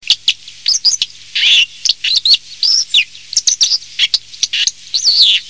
Cincle plongeur
Cinclus cincius
cincle.mp3